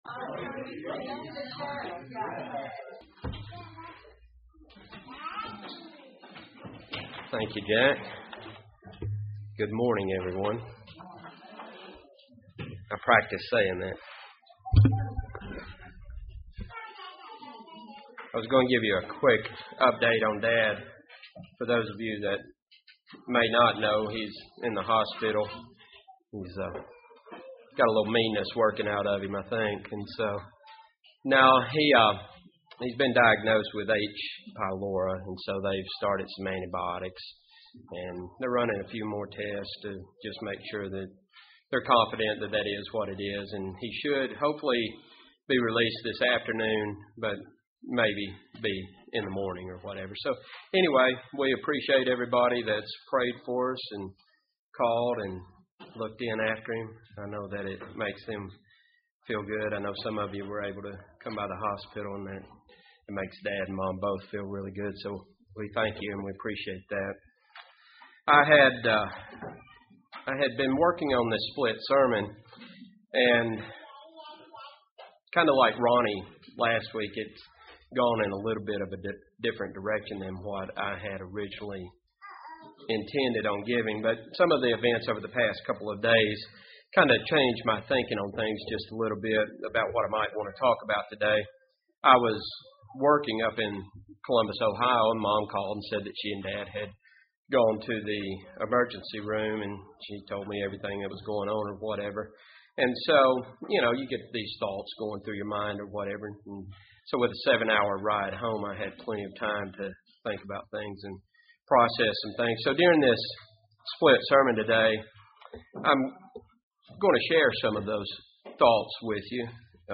The pain of sin is the result of a decision made long ago, but God has a much greater purpose in mind for His children. This sermon takes a closer look at sin, repentance and the ultimate purpose of God for His eternal family.
Given in Huntsville, AL